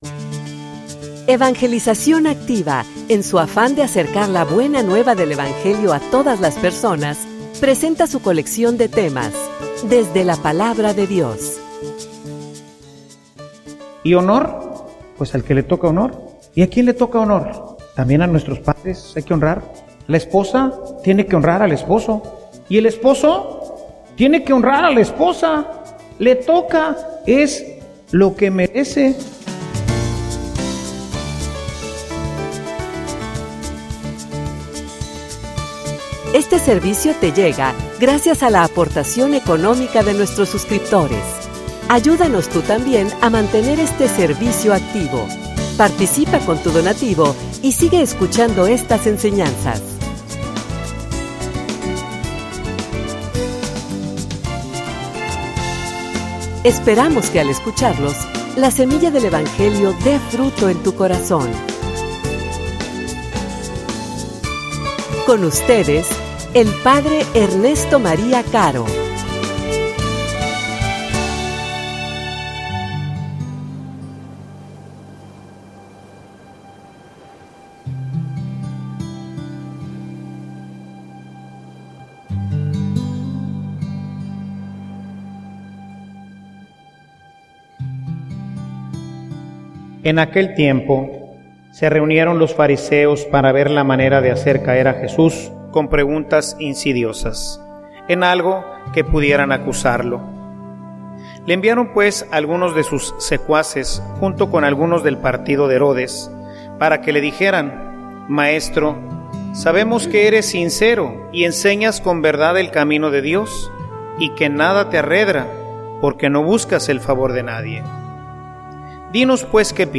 homilia_Dale_a_Dios_lo_que_es_de_Dios.mp3